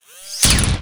smallpist.wav